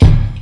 Kick (OKAGA, CA).wav